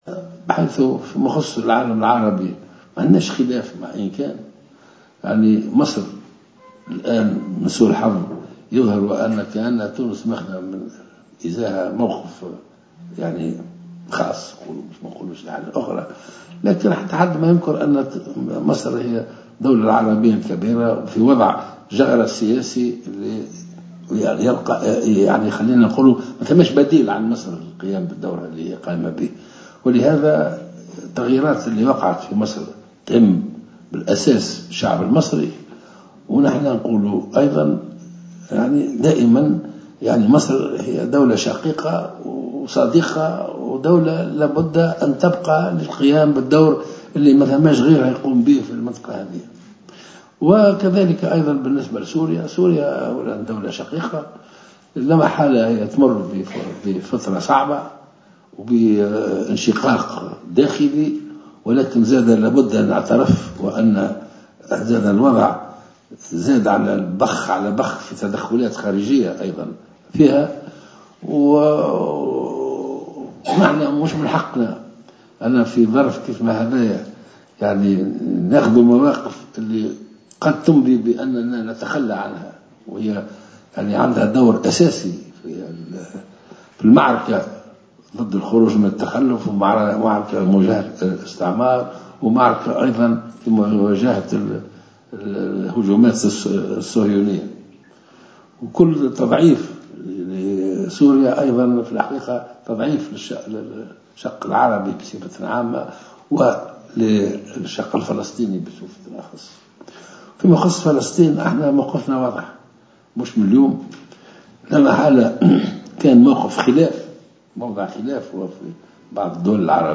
عقدت اليوم الثلاثاء ندوة صحفية لأحزاب قومية وعروبية هي حركة الوحديين وحركة النضال الوطني و حركة ثوابت عبروا فيها عن دعمهم للمرشح الباجي قائد السبسي في الدور الثاني من الانتخابات الرئاسية.
وقد تحدث السبسي خلال هذه الندوة عن موقفه من العلاقات الخارجية لتونس وخاصة مع مصر وسوريا وفلسطين.